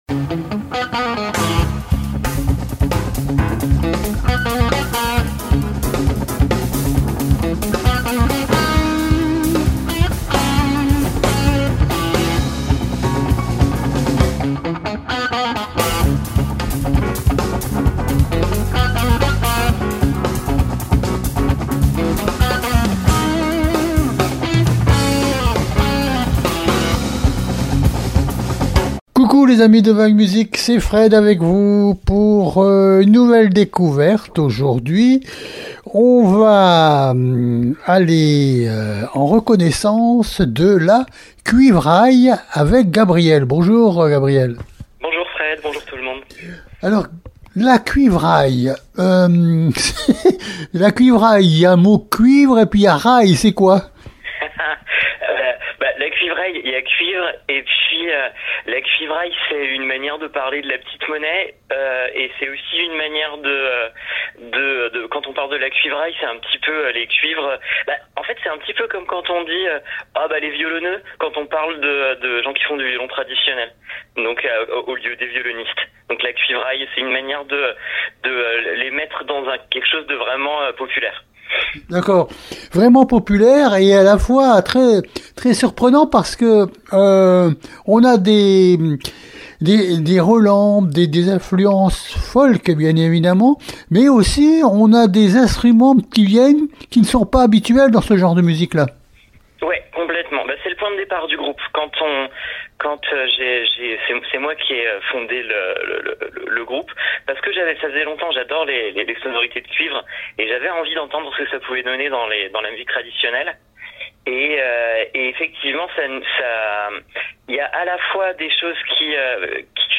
LA CUIVRAILLE-VAG MUSIC interview du 1er décembre 2025